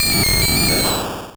Cri de Fantominus dans Pokémon Rouge et Bleu.